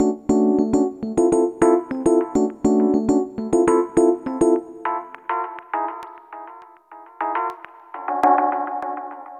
Ala Brzl 1 Piano-B.wav